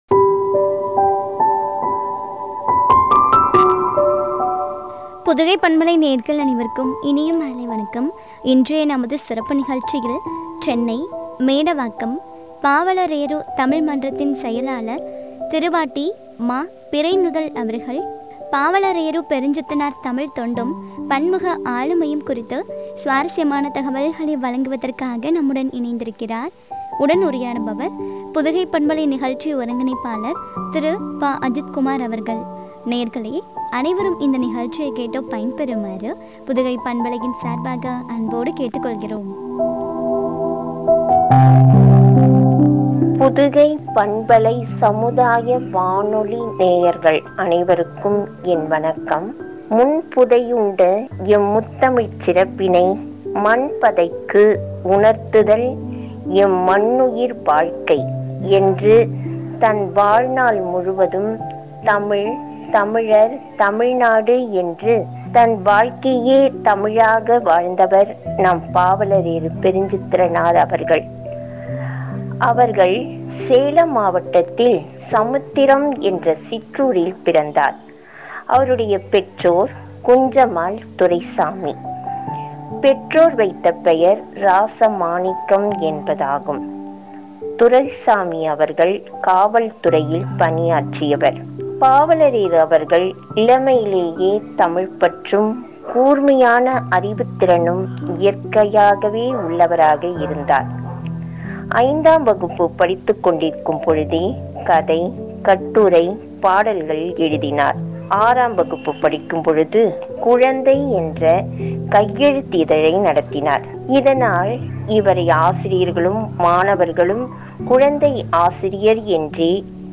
பன்முக ஆளுமையும் குறித்து வழங்கிய உரையாடல்.